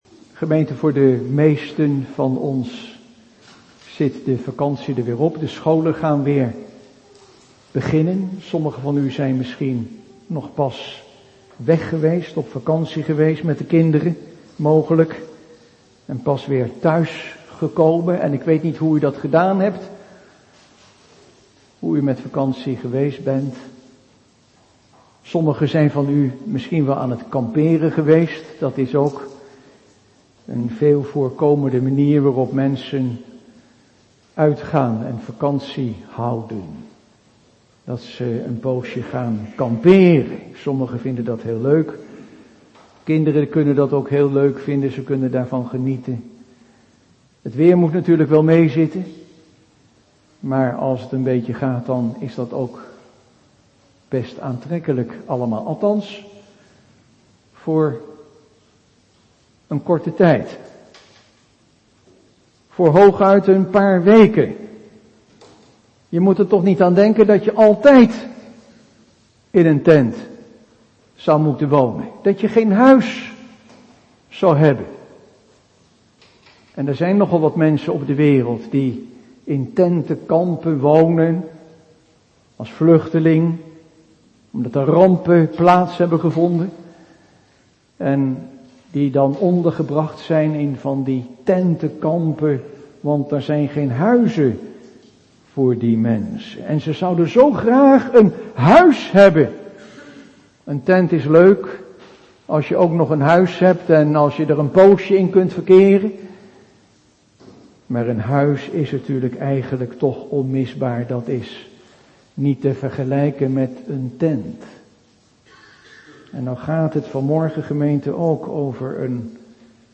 22 augustus 2021 2 Korinthe 5:1 Predikant